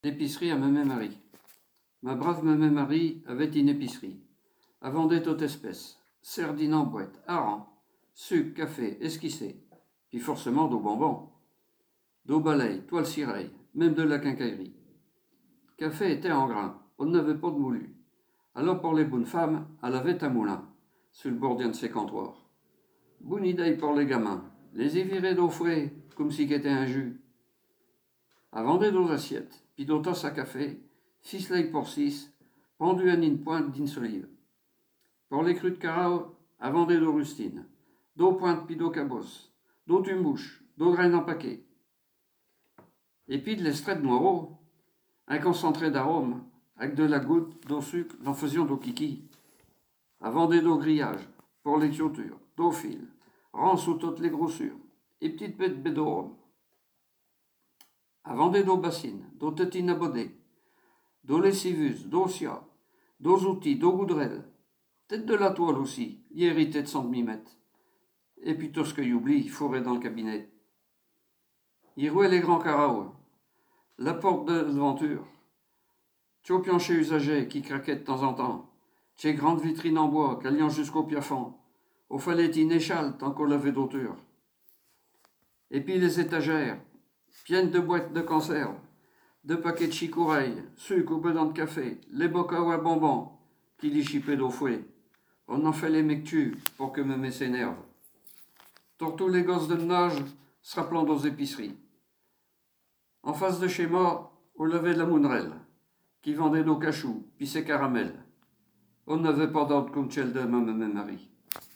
Genre poésie
Catégorie Récit